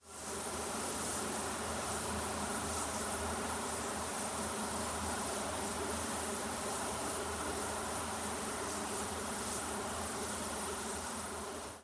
突发事件的声音 " Warp Speed
描述：在Audiopaint制造。
标签： 太空旅行 黑洞 明星战 噪声 科幻 经驱动器 空间 空间船
声道立体声